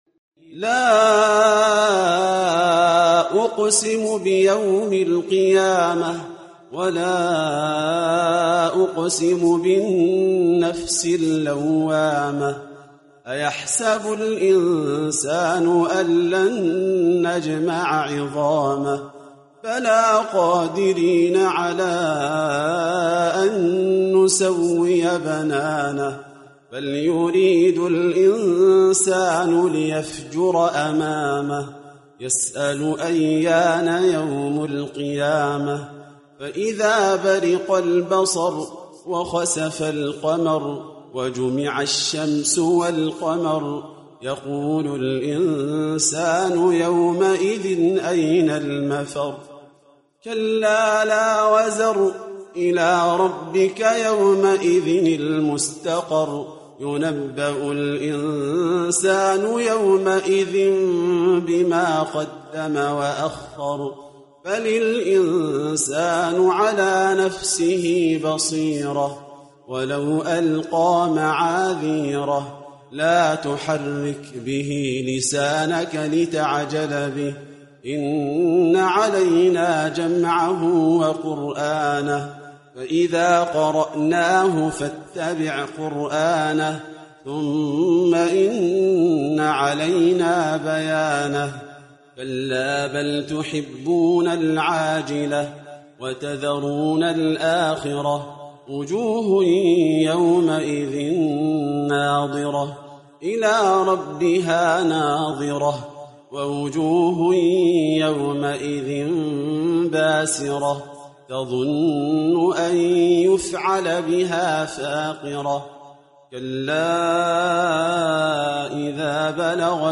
قارئ من دولة السودان